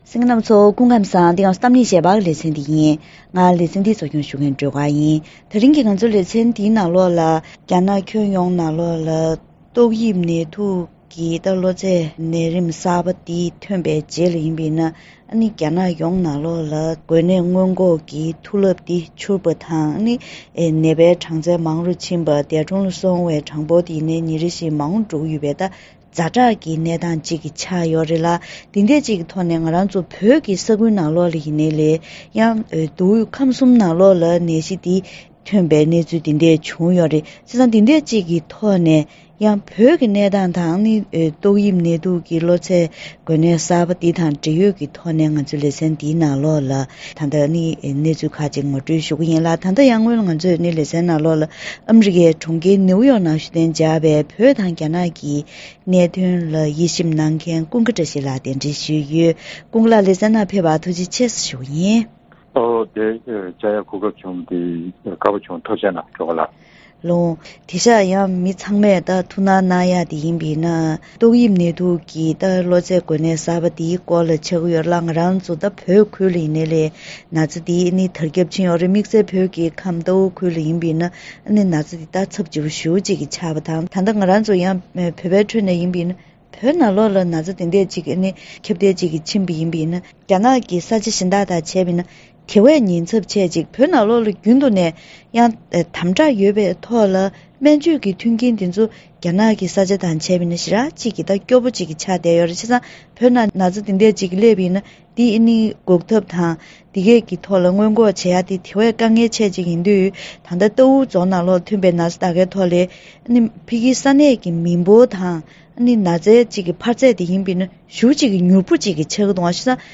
དེ་རིང་གི་གཏམ་གླེང་ཞལ་པར་ལེ་ཚན་ནང་རྒྱ་ནག་ལ་ཏོག་དབྱིབས་ནད་དུག་གི་གློ་ཚད་འགོས་ནད་གསར་པ་འདི་ཁྱབ་གདལ་འགྲོ་བཞིན་ཡོད་པའི་སྐབས་དེར་གཞུང་ཕྱོགས་ཀྱིས་གདོང་ལེན་བྱེད་སྟངས་དང་། བོད་ཁུལ་དུ་འགོས་ནད་འདིའི་དབང་གིས་ཤུགས་རྐྱེན་ཇི་བྱུང་དང་། དམིགས་བསལ་རྣོ་ངར་གྱི་ཟླ་བ་གསུམ་པ་སླེབས་ལ་ཉེ་བའི་སྐབས་དེར་དམ་བསྒྲགས་ཆེ་རུ་འགྲོ་མིན་སོགས་ཀྱི་སྐོར་ལ་བཀའ་མོལ་ཞུས་པ་ཞིག་གསན་རོགས་གནང་།